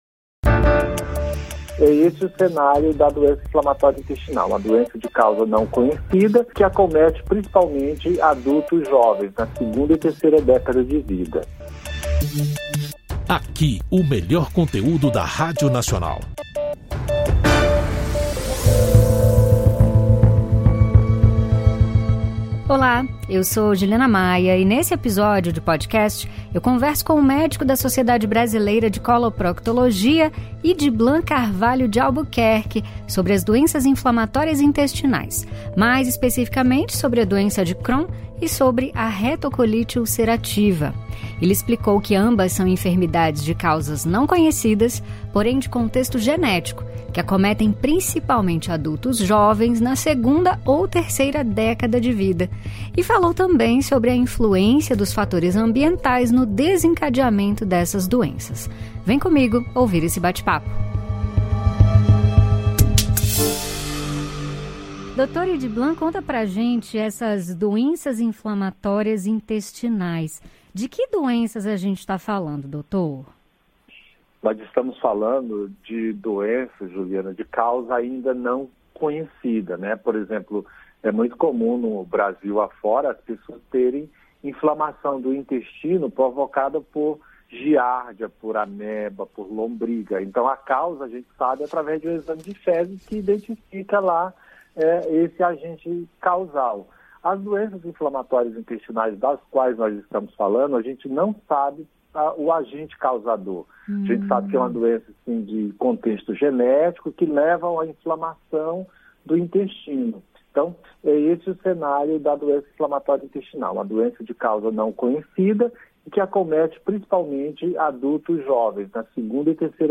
Podcast Entrevista